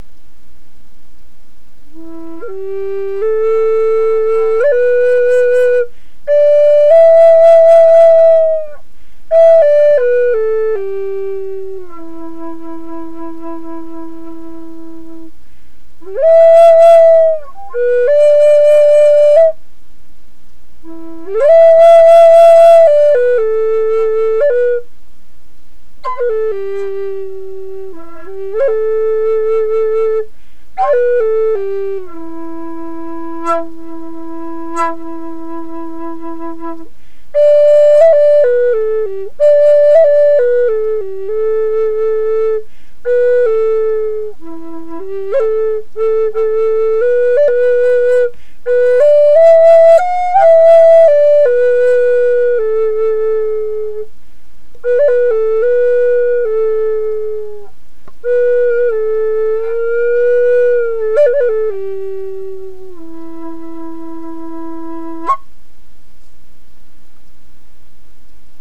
Woodland Style Native American flute in the key E Minor
This Woodland-Style Native American flute is tuned to the key of E and was lovingly created in the Magic Wind workshop.
Bolivian Rosewood